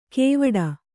♪ kēvaḍa